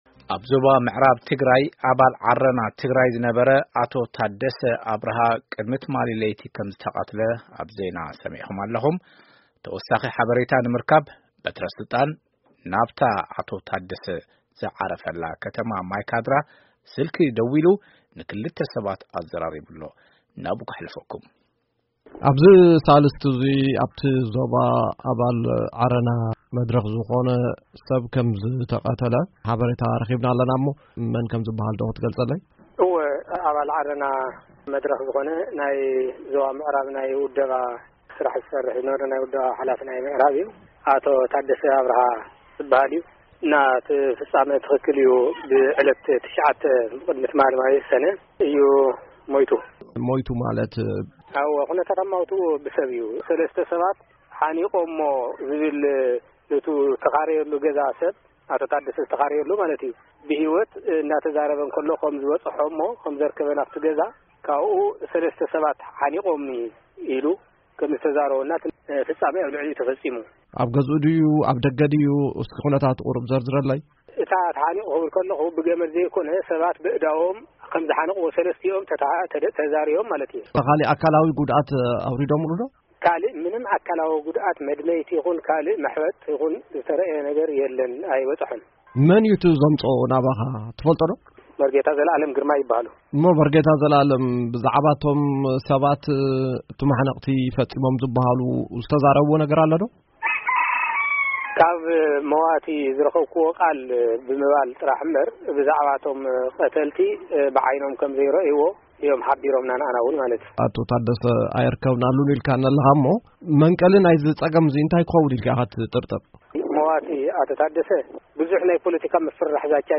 ቃለ-ምልልስ ምስ ወኪል ዓረና ትግራይ